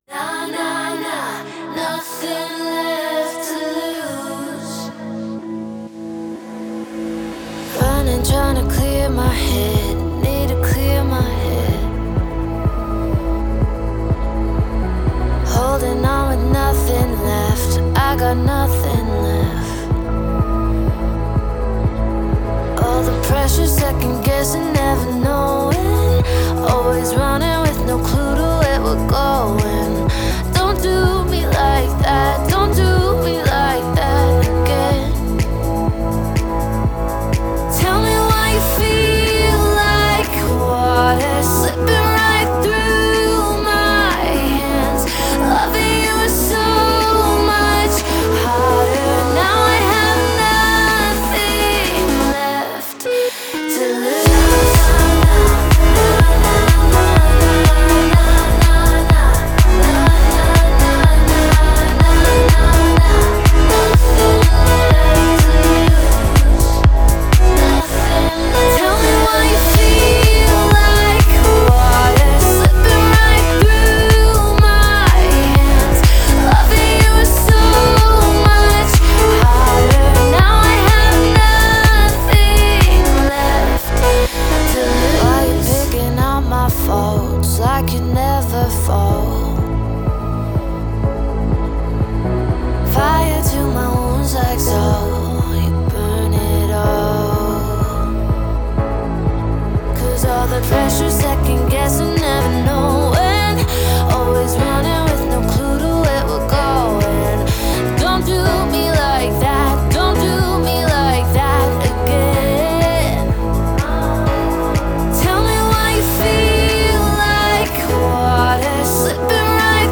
это энергичная поп-музыка с элементами EDM